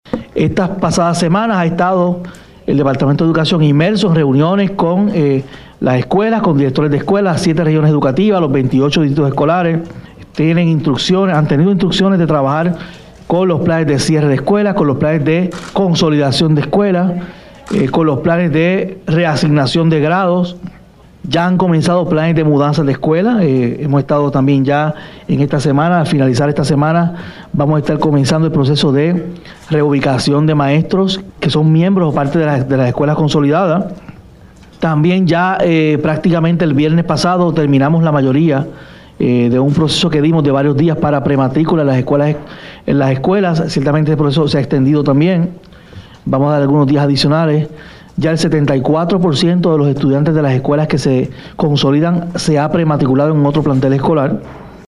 Escuche al Secretario de Educación